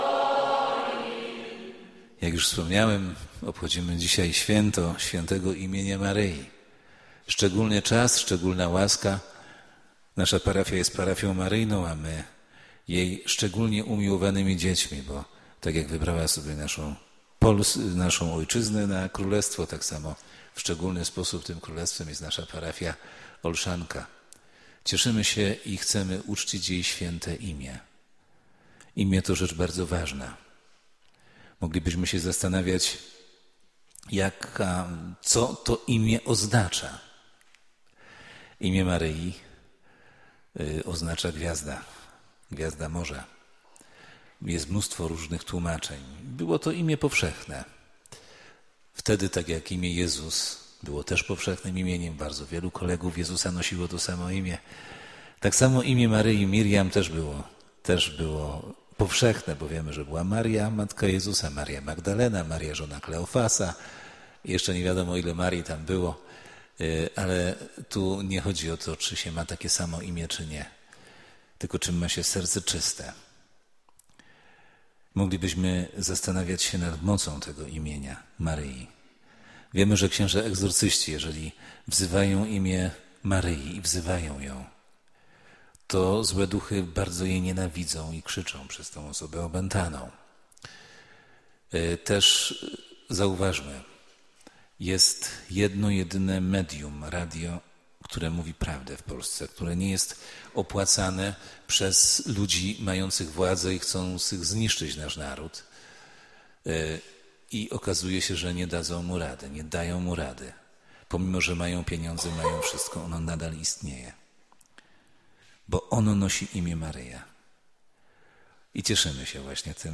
Poniżej kazania wygłoszone we wrześniu w naszej Parafii